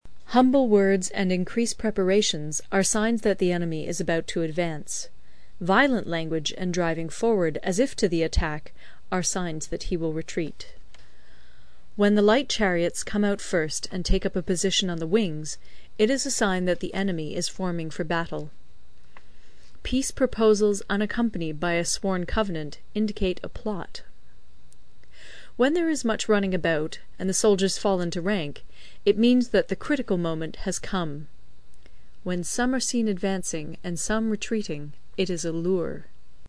有声读物《孙子兵法》第54期:第九章 行军(5) 听力文件下载—在线英语听力室